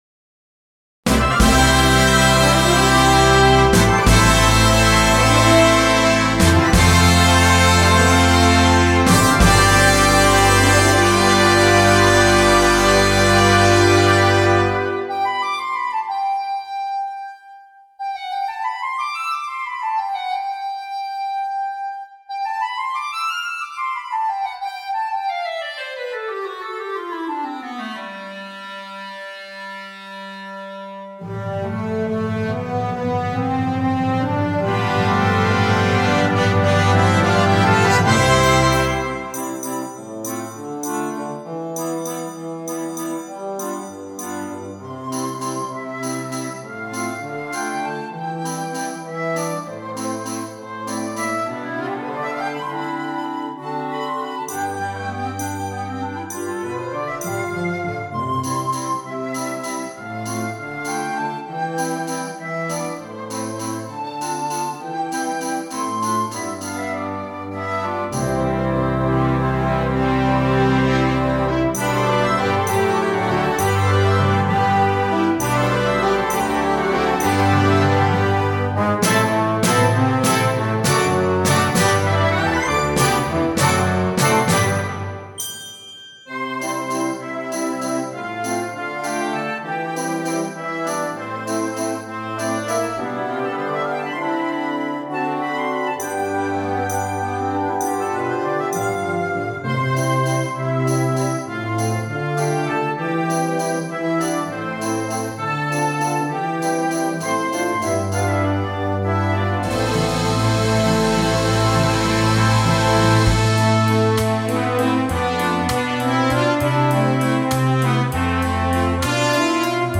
subtle metrical gymnastics
CategoryConcert Band
InstrumentationPiccolo
Trumpets 1-2-3
Timpani
Glockenspiel